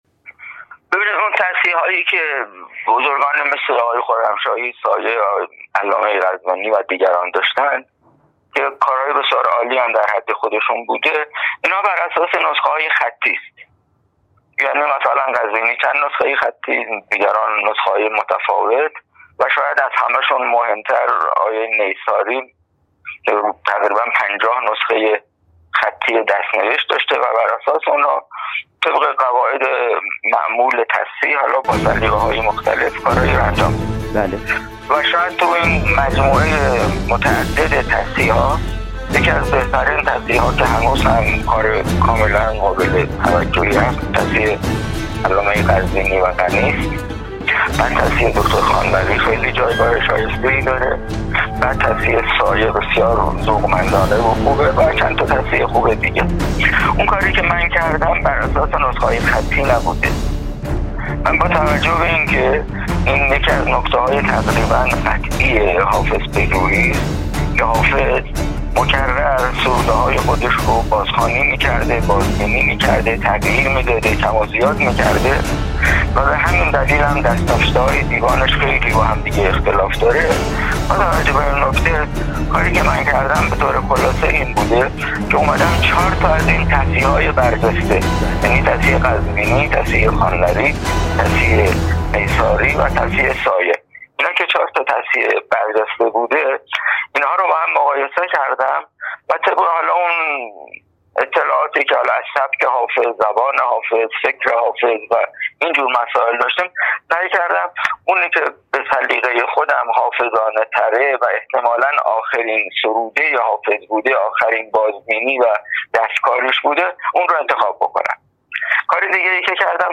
بربلندای امواج تلفن